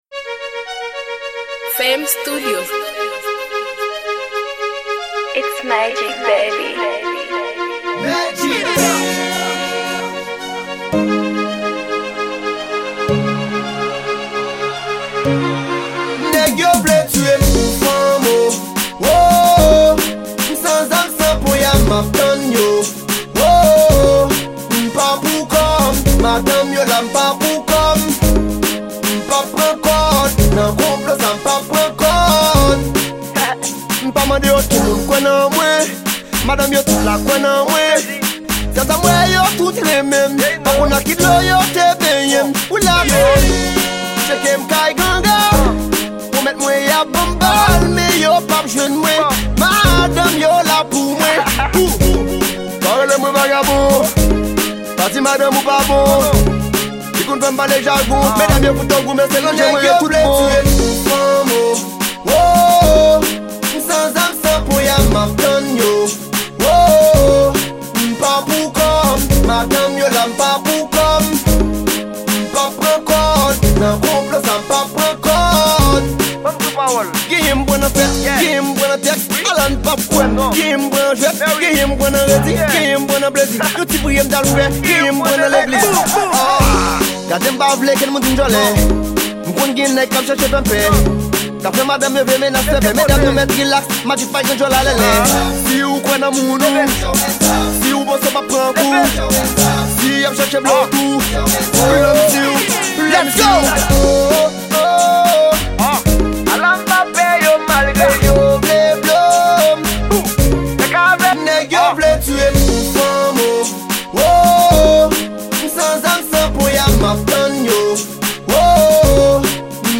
Genre: ridim.